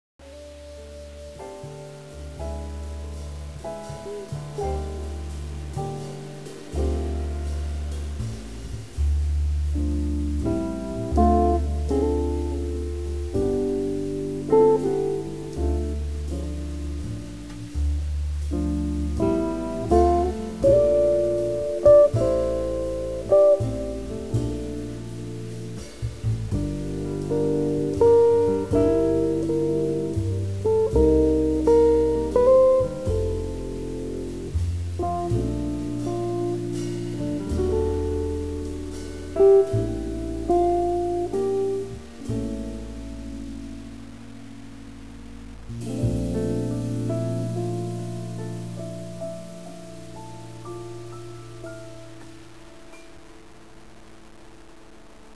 acoustic bass, piccolo bass
drums and percussion
Recorded May 1996 at Right Track Studio NYC